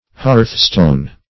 Hearthstone \Hearth"stone`\ (-st[=o]n`), n.